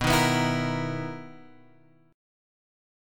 B Major 11th
BM11 chord {7 6 9 9 x 6} chord